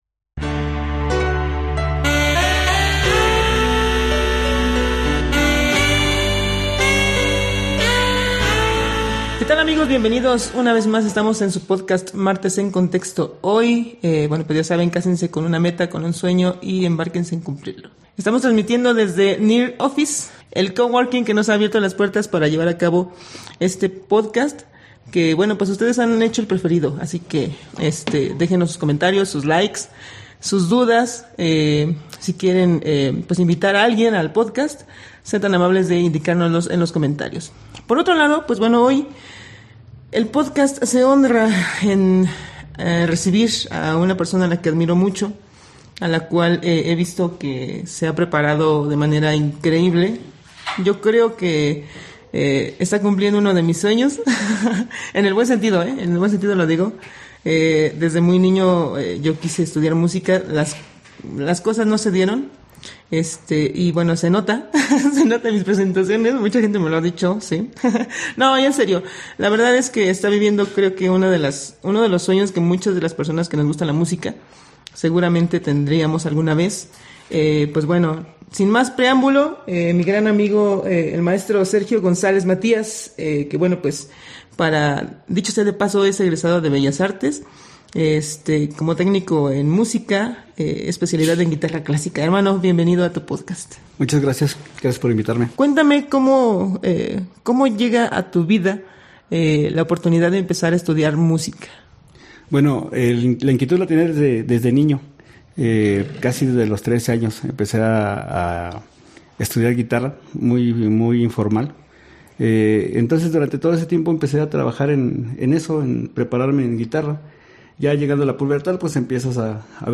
Locación: NEAR OFFICE.